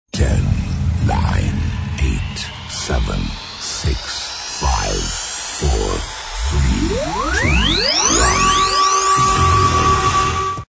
Kermis Jingle's  2013
Countdown 10-0
Jingle-14-Countdown 10-0-.mp3